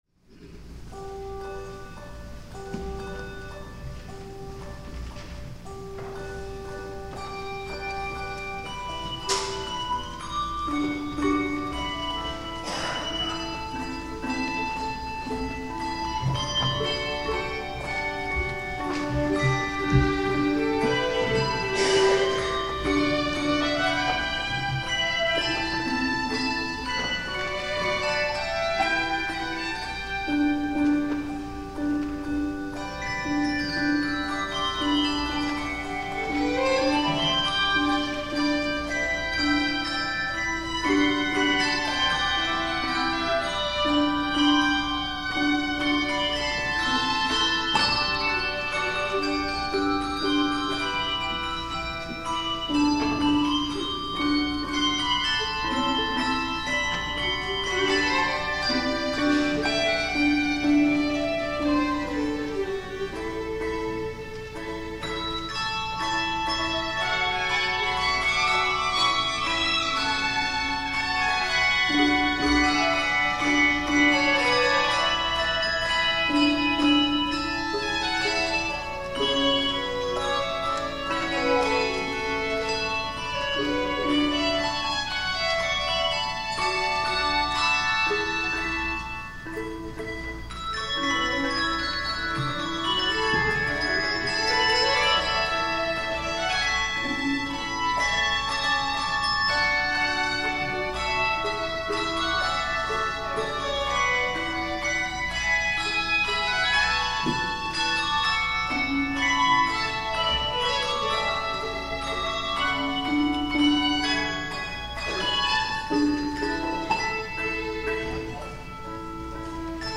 THE OFFERTORY
violin